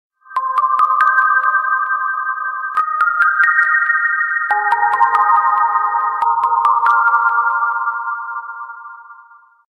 • Качество: 128, Stereo
загадочные